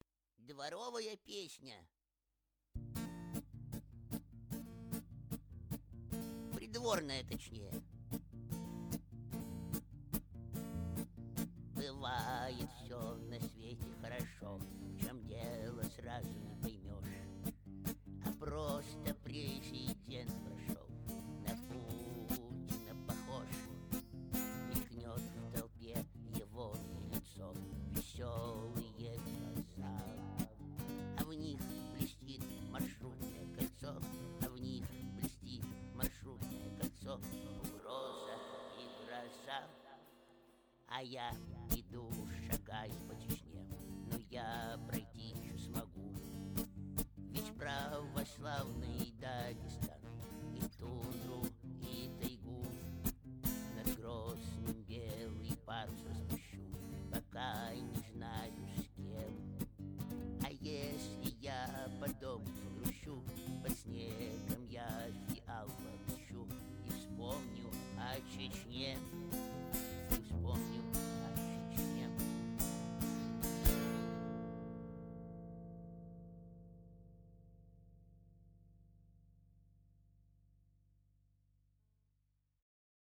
Дворовая песня.